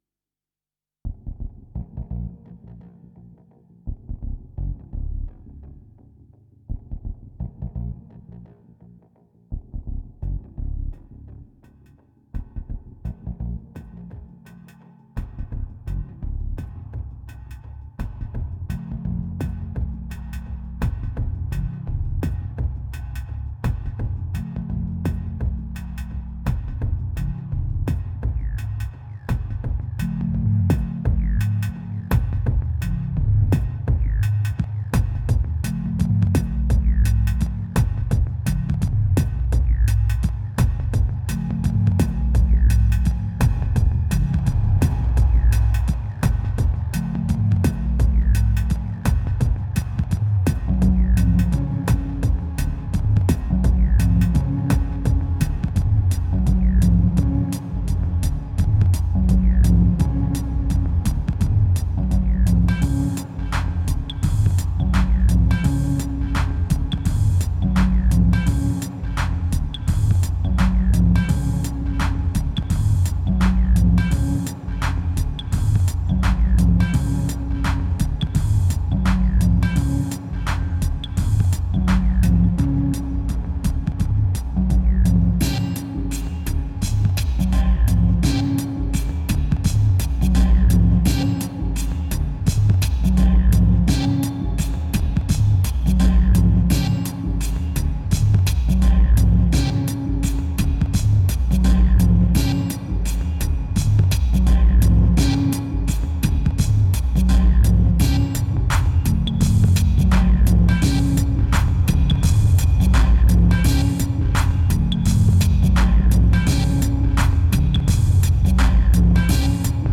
2482📈 - -53%🤔 - 85BPM🔊 - 2011-08-12📅 - -351🌟